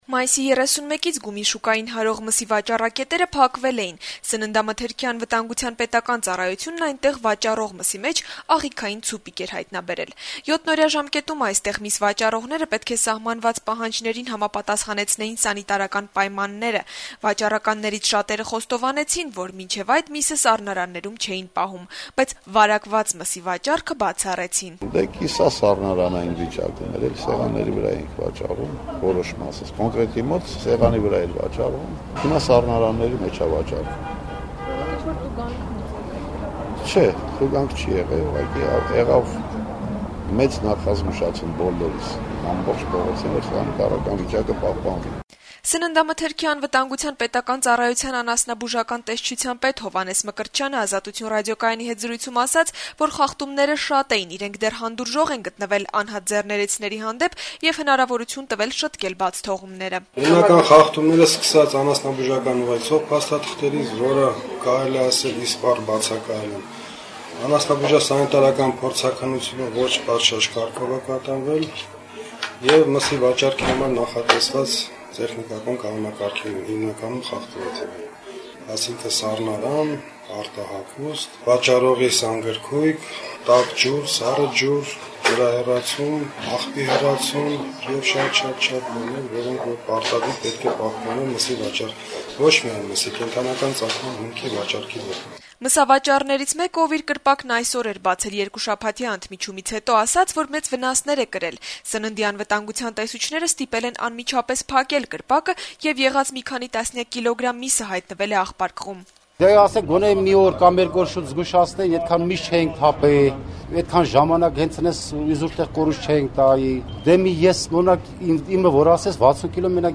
Սննդամթերքի անվտանգության պետական ծառայության անասնաբուժական տեսչության պետ Հովհաննես Մկրտչյանը «Ազատություն» ռադիոկայանի հետ զրույցում ասաց, որ խախտումները շատ էին, իրենք դեռ հանդուրժող են գտնվել անհատ ձեռներեցների հանդեպ եւ հնարավորություն տվել շտկել բացթողումները: